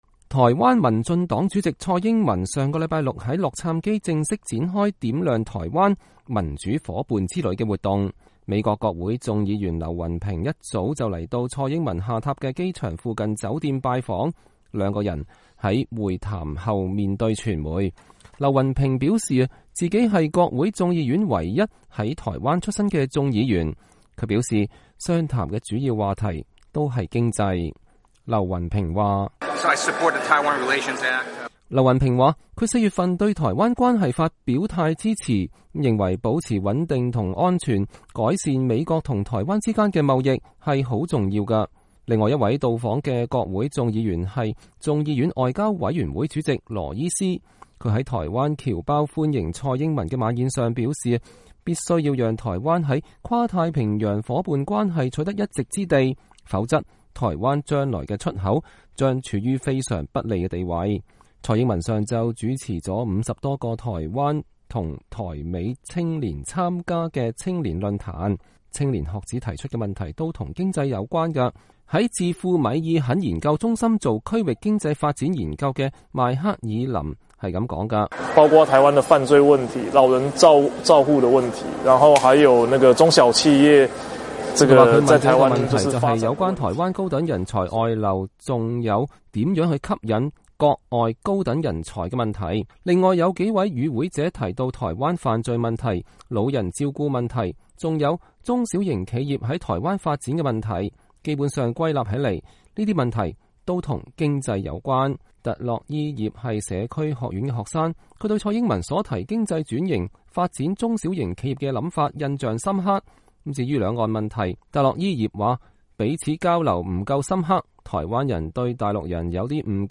餐會的掌聲和歡呼此起彼落。